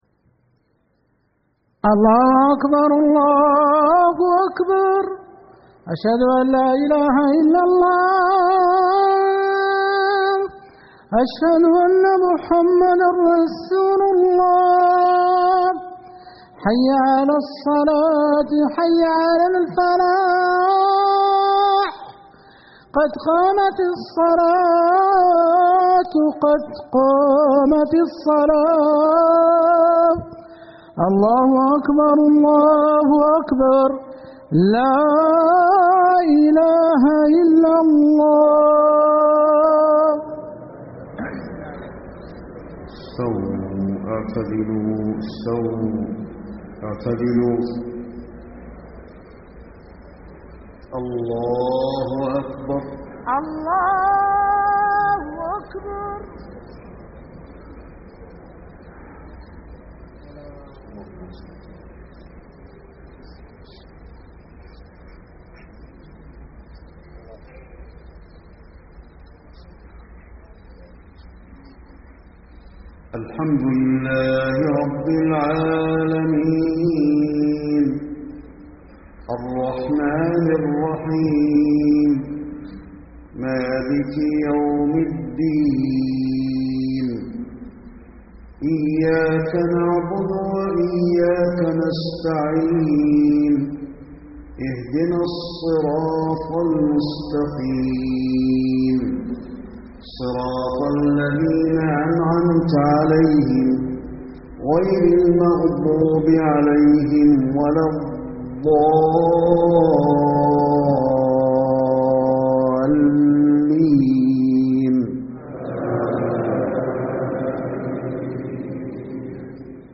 صلاة العشاء 7-7-1434هـ سورتي الانفطار و الّليل > 1434 🕌 > الفروض - تلاوات الحرمين